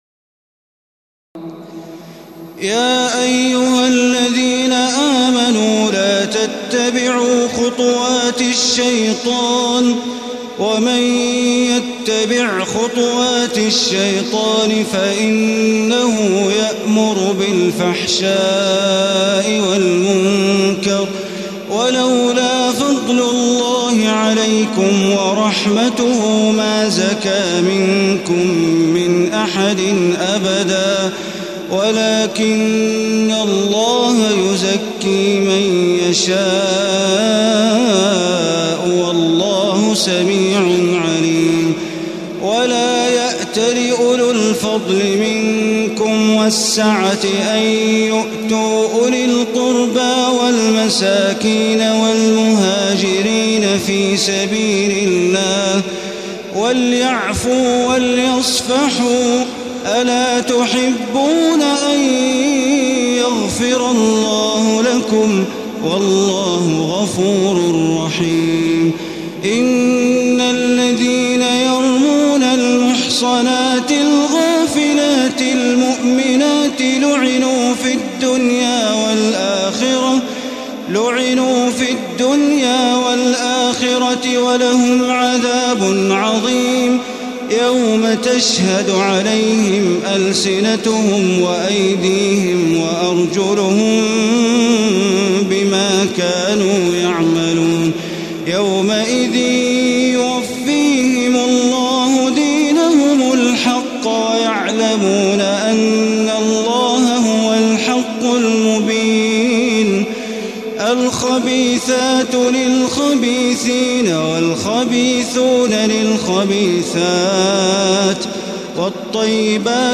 تراويح الليلة الثامنة عشر رمضان 1435هـ من سورتي النور (21-64) و الفرقان (1-20) Taraweeh 18 st night Ramadan 1435H from Surah An-Noor and Al-Furqaan > تراويح الحرم المكي عام 1435 🕋 > التراويح - تلاوات الحرمين